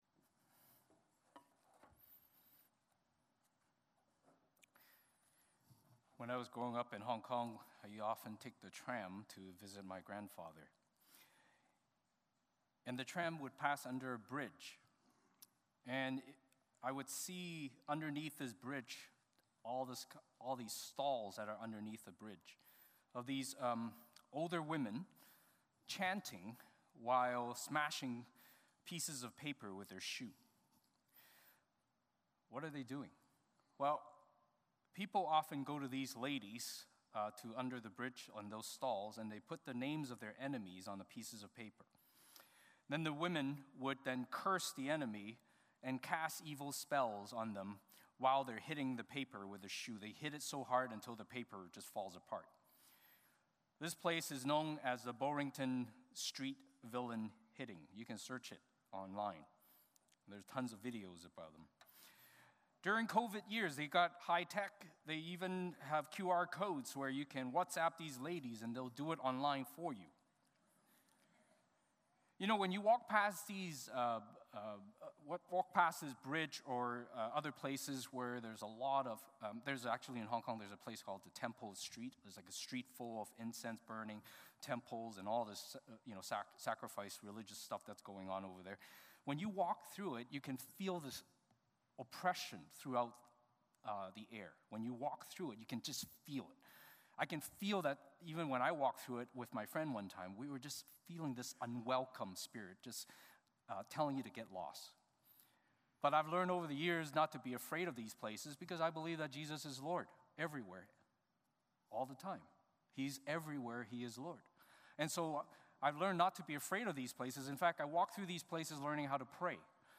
43-48 Service Type: Sunday Morning Service Passage